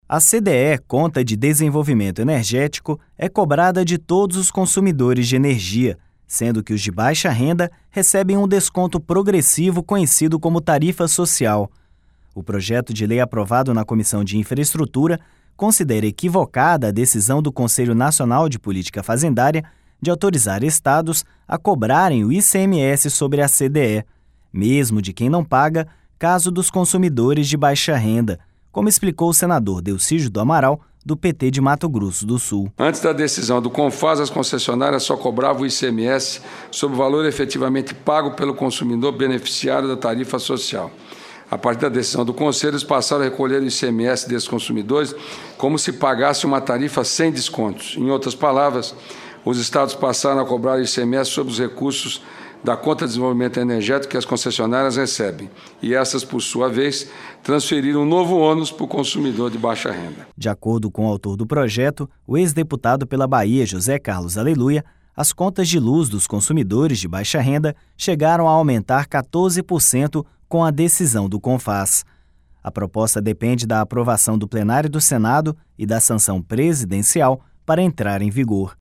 Mesmo de quem não paga, caso dos consumidores de baixa renda, como explicou o senador Delcídio do Amaral, do PT de Mato Grosso do Sul.